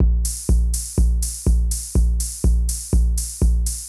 ENE Beat - Mix 7.wav